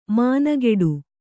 ♪ mānageḍu